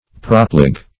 Propleg \Prop"leg`\, n. [So called because it props up or